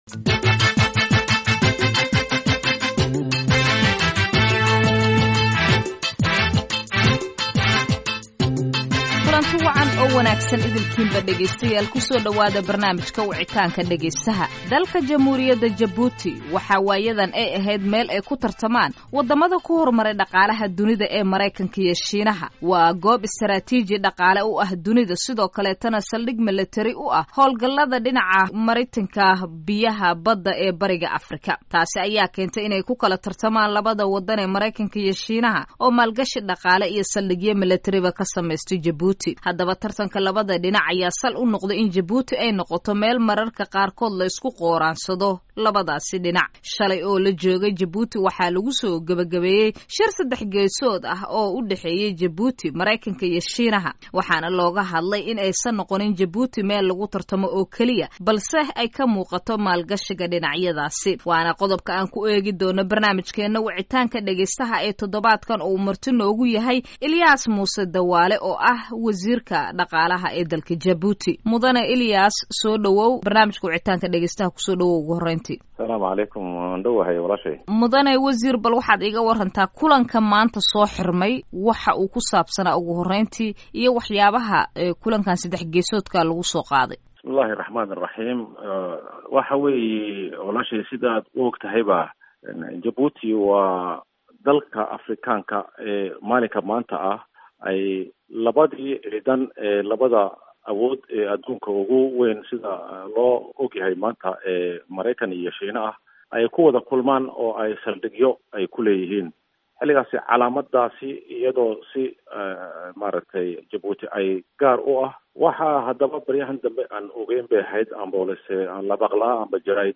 Barnaamijka Wicitaanka Dhageystaha todobaakan waxaa marti ku ah, Wasiirka Maaliyadda dalka Jabuti, Ilyas Muse Dawale.